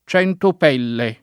vai all'elenco alfabetico delle voci ingrandisci il carattere 100% rimpicciolisci il carattere stampa invia tramite posta elettronica codividi su Facebook centopelle [ ©H ntop $ lle ] o centopelli [ ©H ntop $ lli ] s. m. (zool.); pl. ‑li